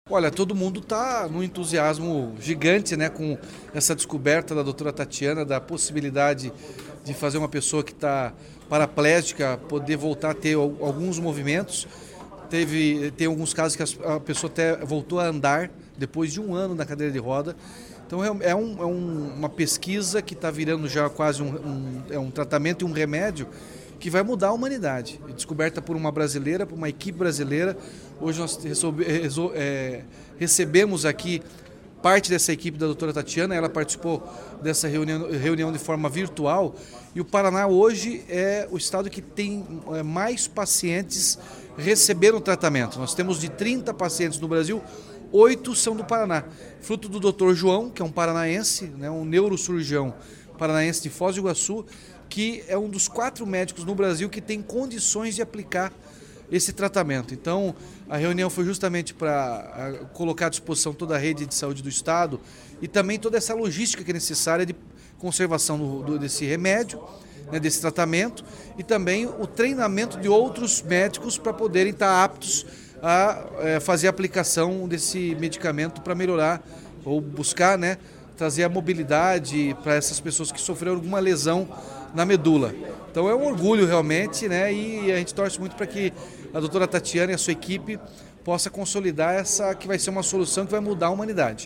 Sonora do governador Ratinho Junior sobre as aplicações de polilaminina no Paraná